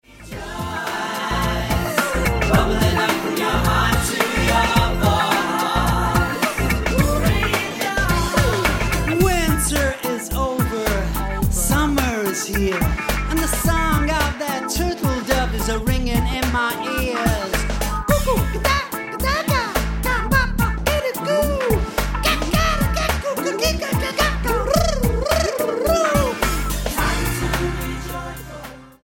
The eccentric British rock dance act Anarchic